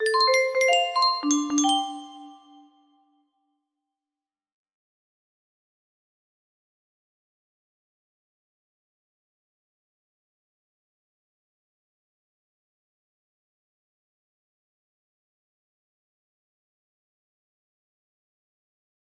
music box melody
Grand Illusions 30 (F scale)